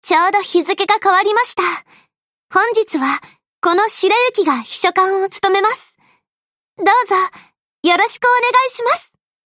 Ship_Voice_Shirayuki_Kai_00.mp3